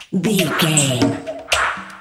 Electronic loops, drums loops, synth loops.,
Epic / Action
Fast paced
In-crescendo
Ionian/Major
Fast
industrial
driving
heavy
hypnotic